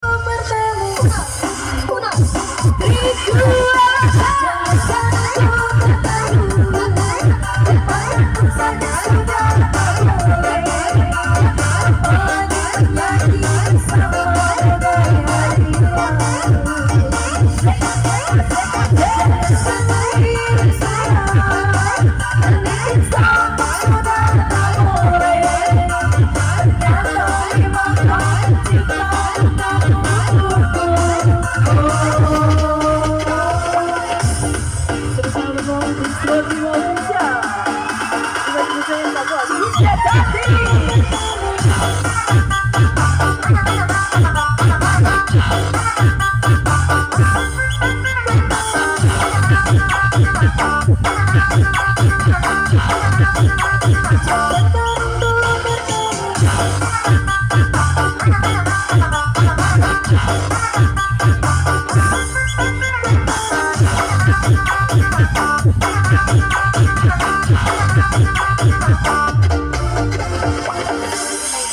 special performance